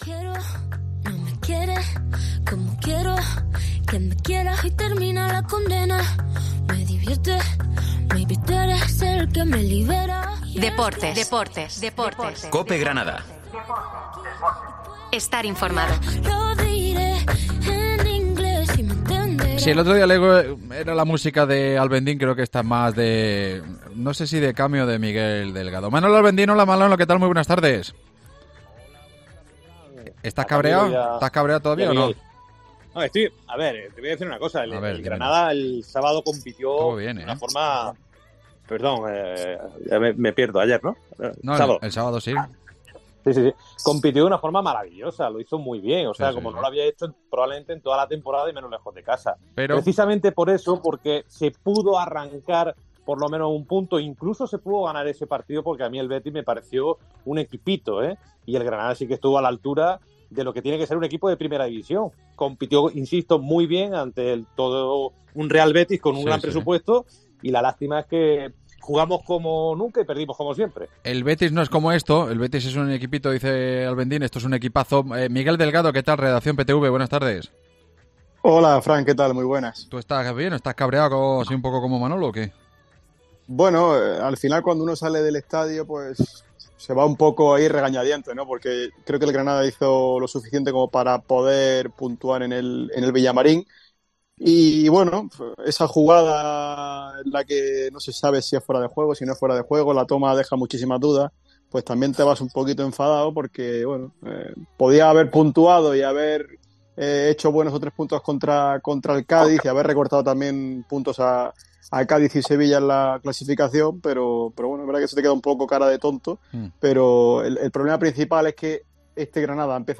Analizamos en nuestra tertulia las jugadas polémicas del partido entre el Betis y el Granada